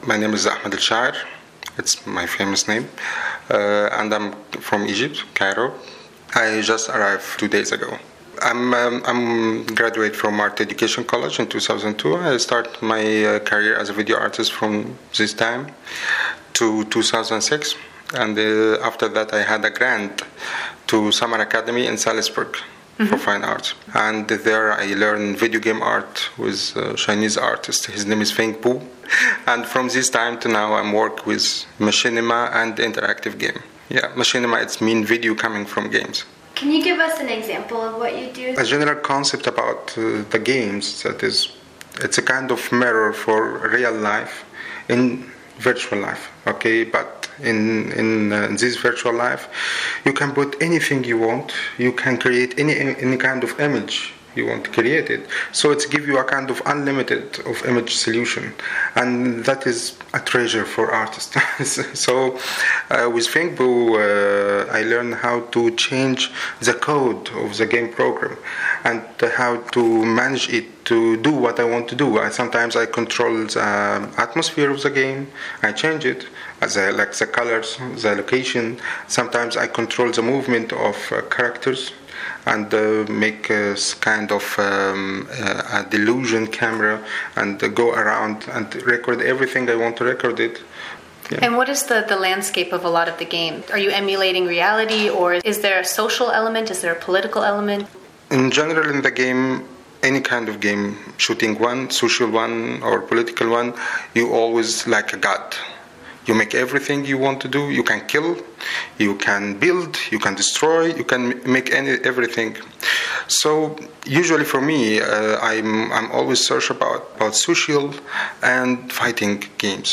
In this interview, he discusses his own practice at length, and addresses the challenges he faced while attempting to get a visa in order to attend Omi.